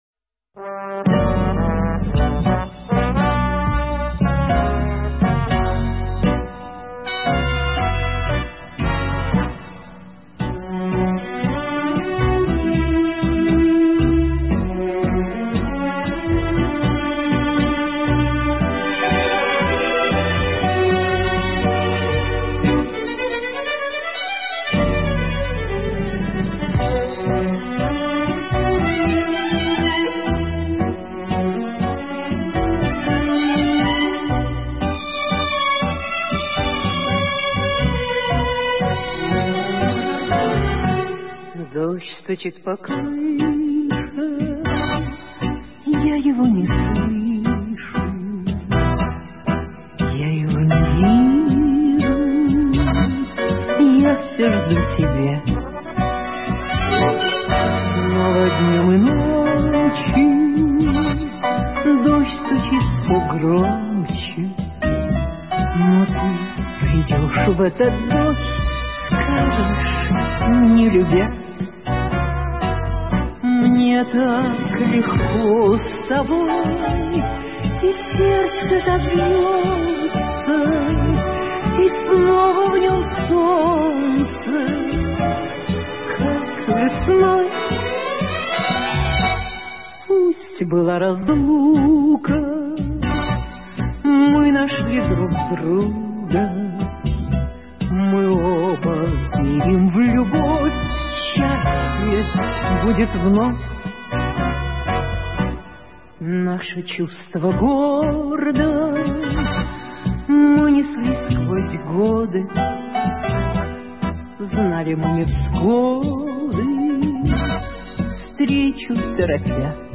Сегодня нас ждёт встреча с танго из далёкого 1934 года